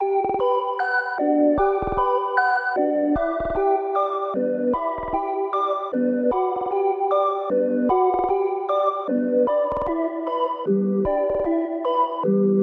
合成器 泡泡糖
Tag: 152 bpm Trap Loops Synth Loops 2.13 MB wav Key : C